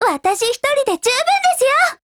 贡献 ） 协议：Copyright，其他分类： 分类:少女前线:MP5 、 分类:语音 您不可以覆盖此文件。